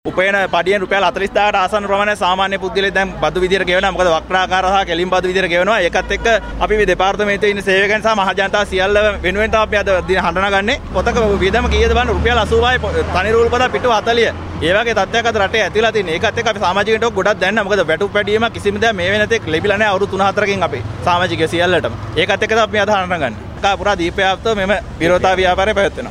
විරෝධතාවට එක්ව සිටි තැපැල් සේවකයෙකු මෙලෙස අදහස් පළ කළා.